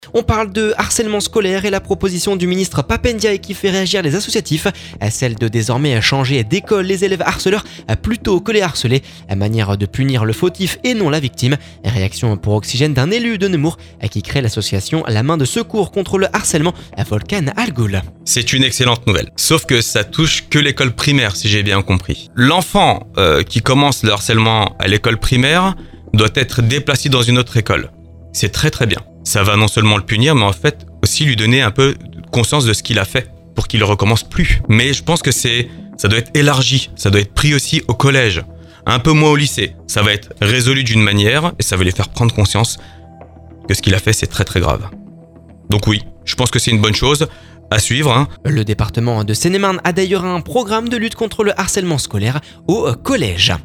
Réaction pour Oxygène d'un élu de Nemours qui crée l'association La Main de secours, contre le harcèlement, Volkane Algul.